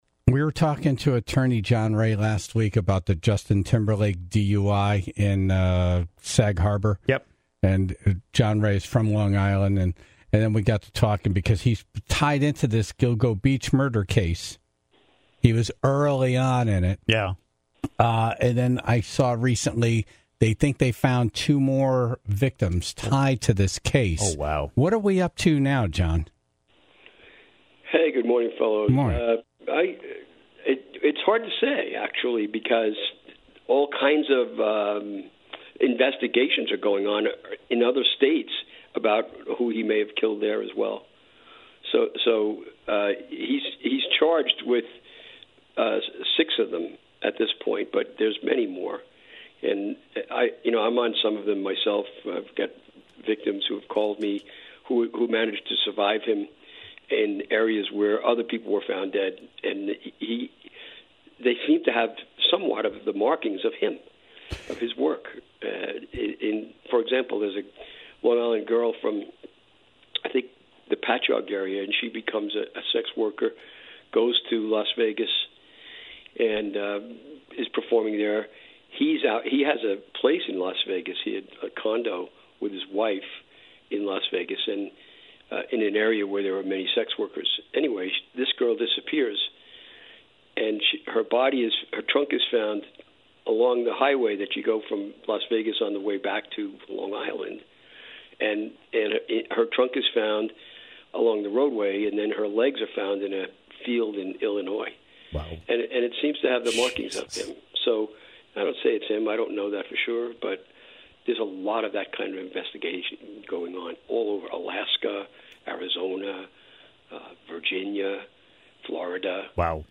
While he was on, a Tribe member called in to share a hair-raising encounter with the man believed to be responsible for the murders back in 2007.